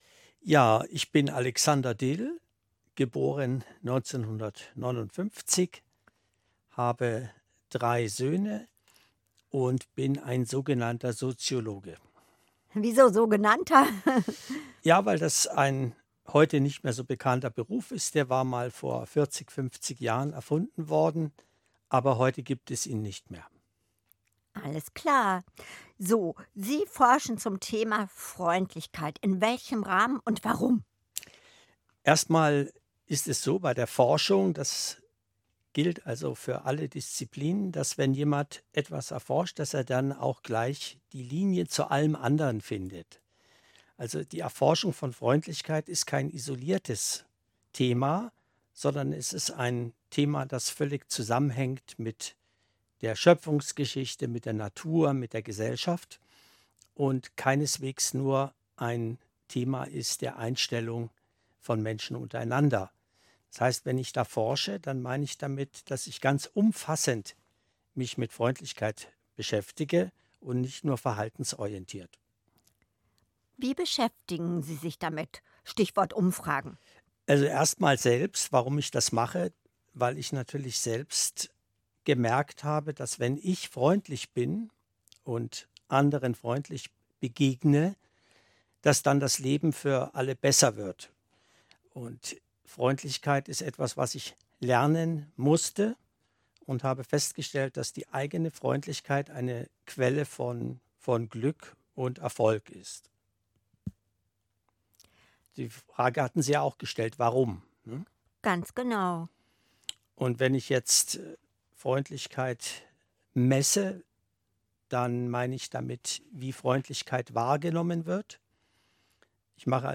im Studio des Bayerischen Rundfunks für den WDR ein halbstündiges Interview